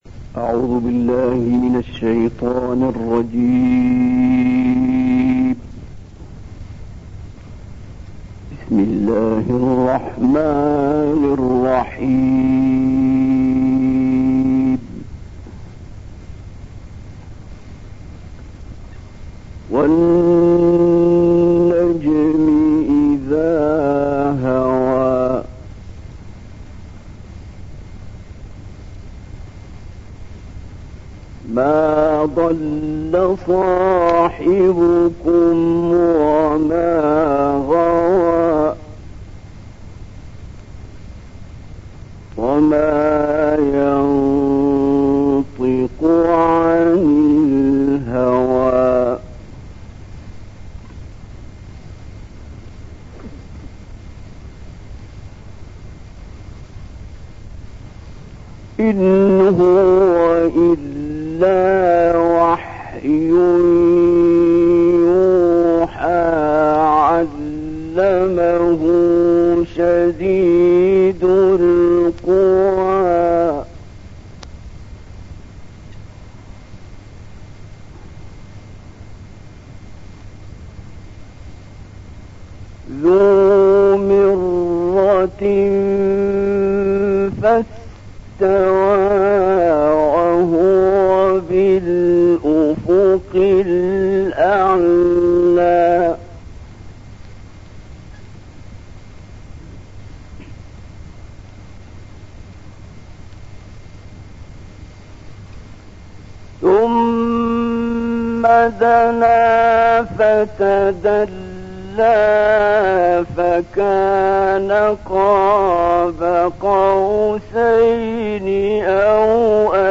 تلاوت سوره نجم با صدای «عبدالباسط» در دفتر روزنامه مصری
گروه فعالیت‌های قرآنی: تلاوتی جدید از استاد عبدالباسط در فضای مجازی منتشر شده است که استاد در دفتر روزنامه مصری(الاهرام) آن را اجرا کرده است.
این تلاوت کوتاه از آیات 1 تا 18 سوره نجم را استاد در دفتر روزنامه مصری «الأهرام» و به مناسبت معراج نبی اکرم(ص) در دهه هشتاد میلادی اجرا کرده است.